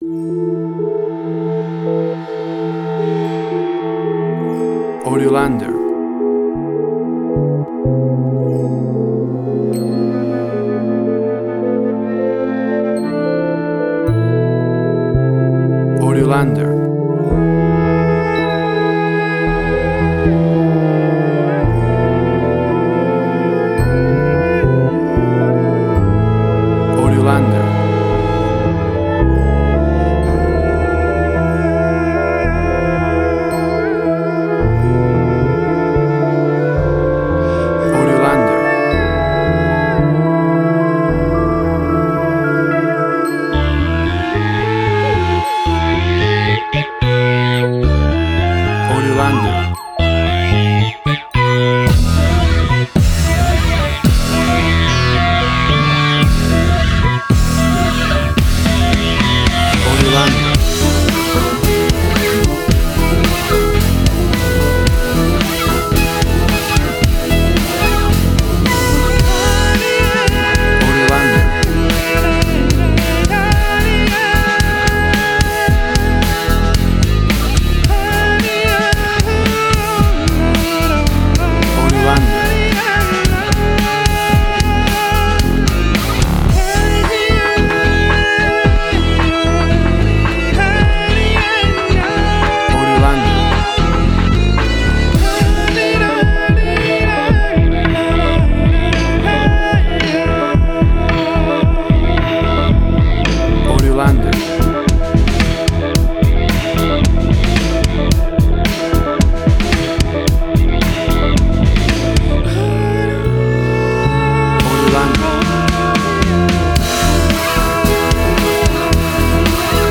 Mysterious rich, world circus style, powerfull music.
Tempo (BPM): 112